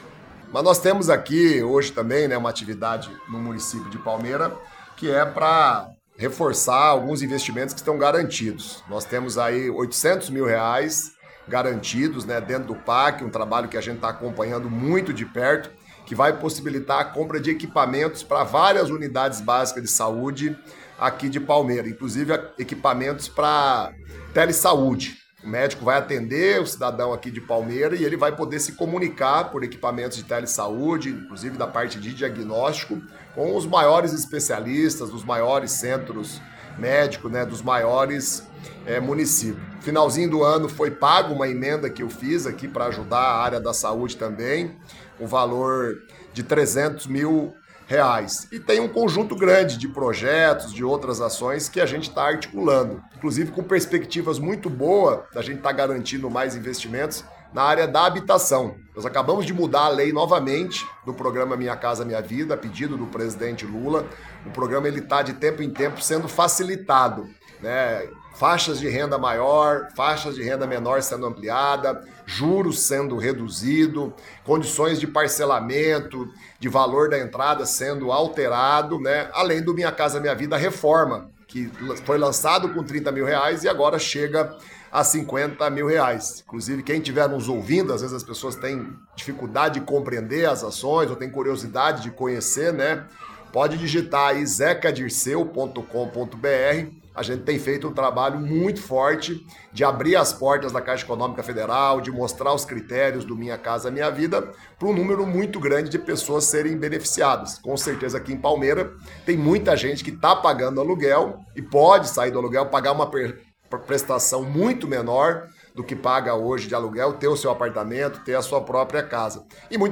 Antes, porém, o deputado esteve na Rádio Cruzeiro do Sul, quando conversamos com ele sobre a programação da visita ao município.